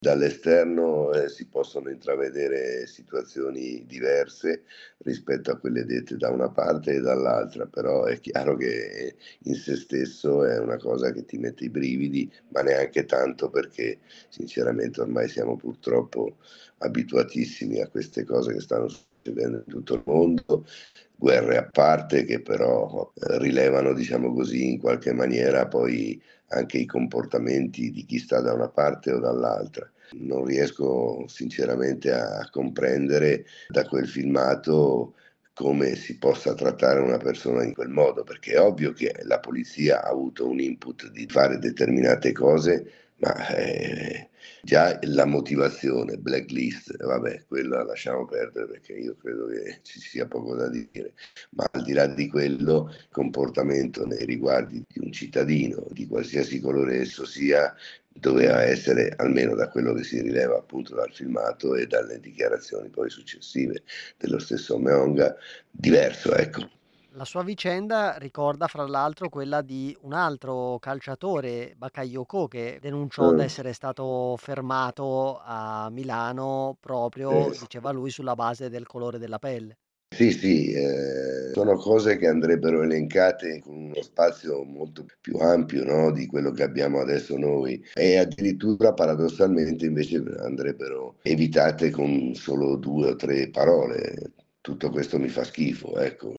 Abbiamo intervistato Claudio Onofri, ex capitano del Genoa, bandiera della squadra rossoblu.